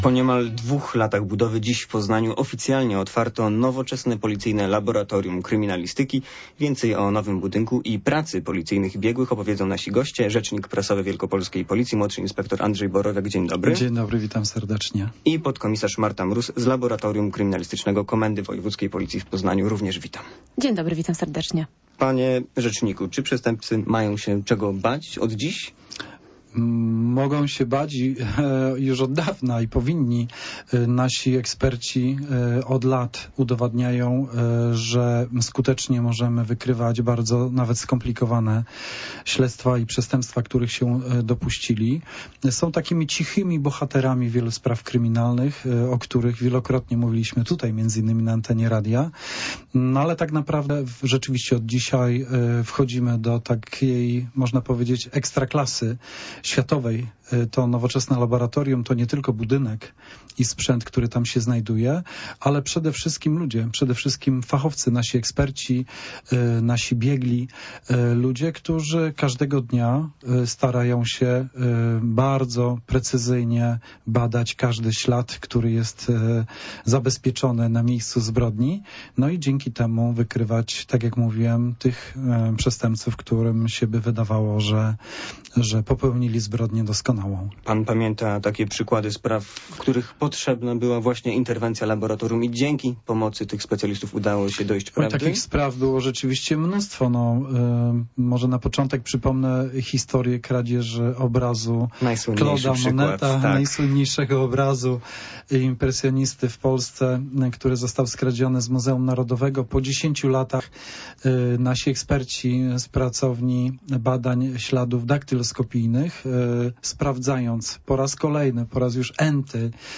Popołudniowa rozmowa Radia Poznań - 21.10.2024